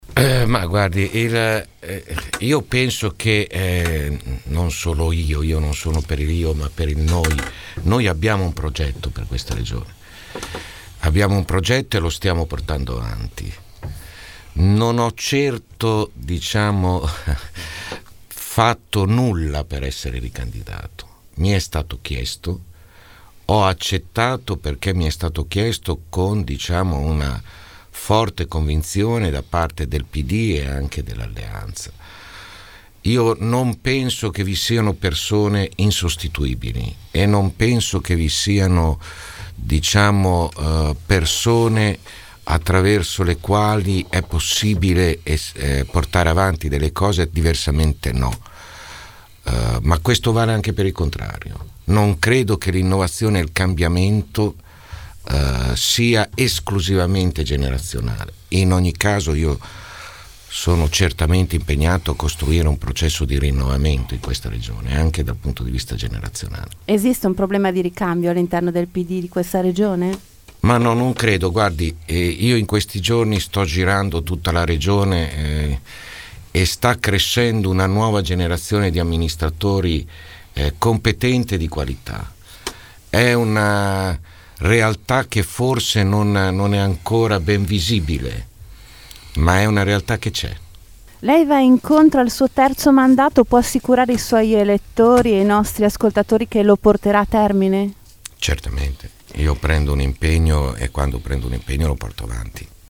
16 mar. – Si concludono con il presidente uscente e candidato del centrosinistra, Vasco Errani, le interviste di Città del Capo – Radio Metropolitana ai candidati alle prossime elezioni regionali (28 – 29 marzo).